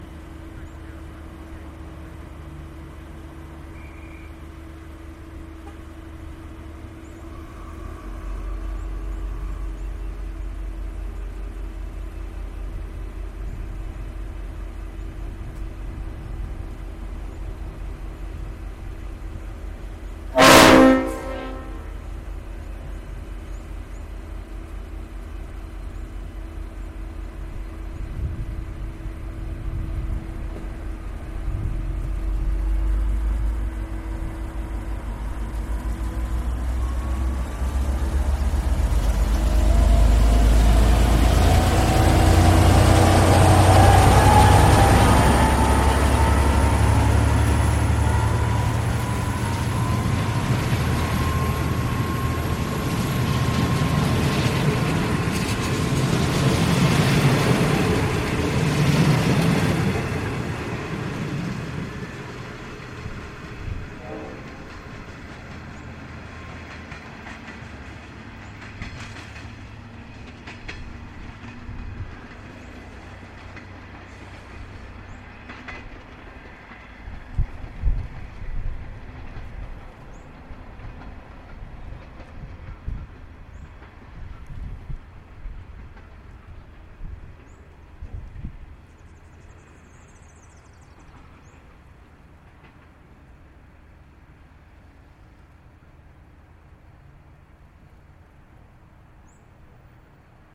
The 5:50 Passenger train leaving Warrnambool station 9/7/15
The whistle blows the N class locomotive sounds the horn and powers off and then its gone leaving just the birds chirping again